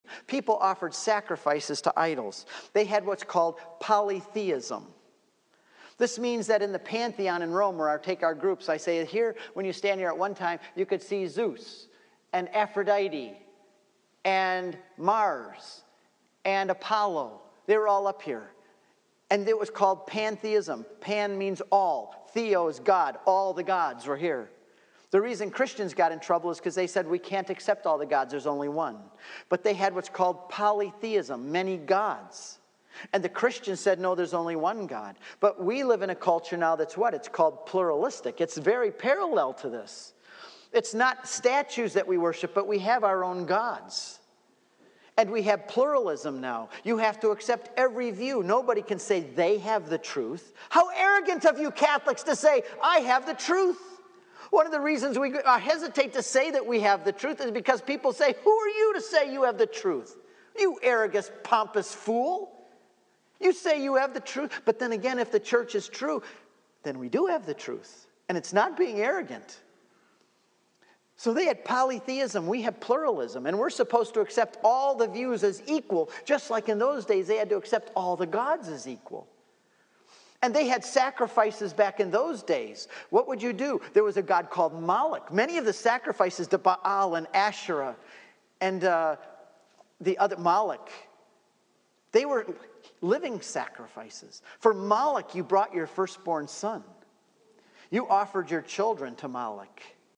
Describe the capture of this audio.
Speaking to Men’s Groups are one of my favorite things to do. These guys were hungry to learn and to act like mature Catholic men in a pagan world — and we had fun too!